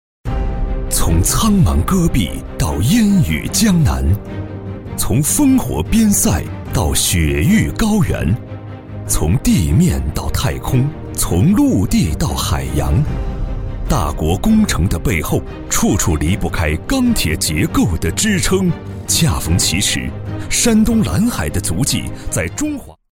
Vídeos corporativos
Clean audio with no breaths or mouth noises
Sennheiser MKH 416 Mic, UA Volt 276 Interface, Pro Recording Booth, Reaper
BarítonoBajo